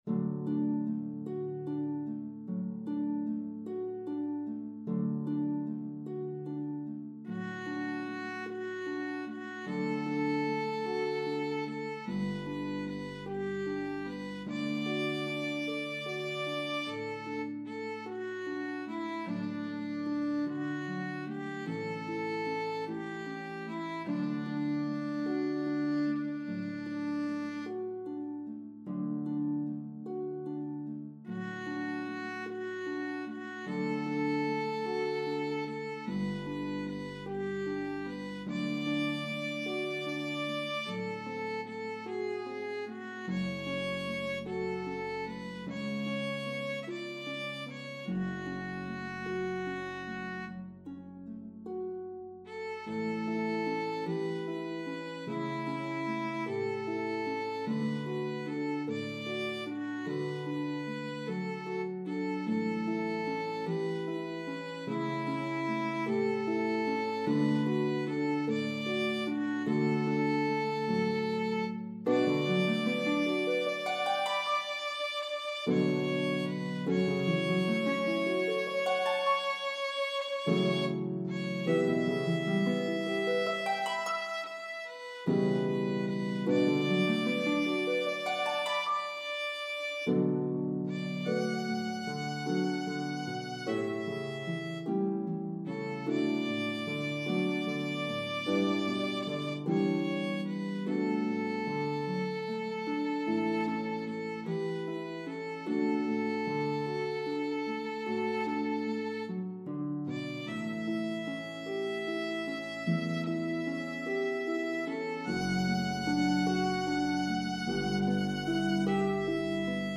The melody of the two verses varies in rhythm & pitches.